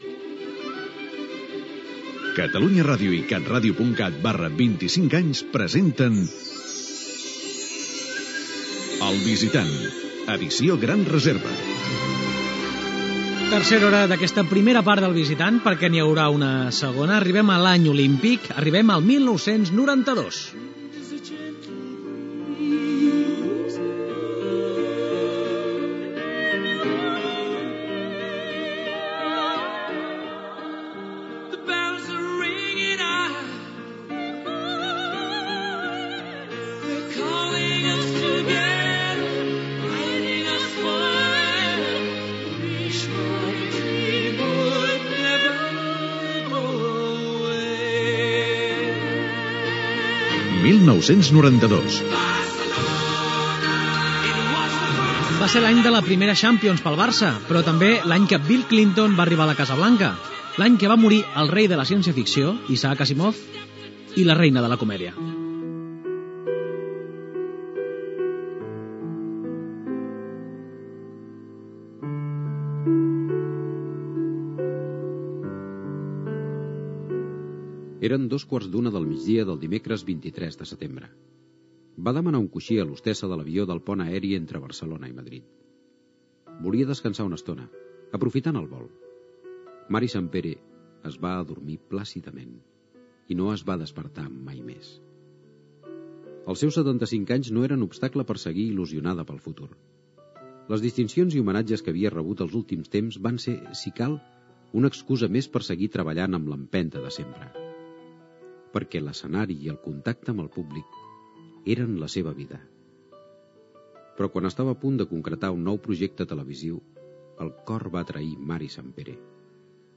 Careta del programa.
Entreteniment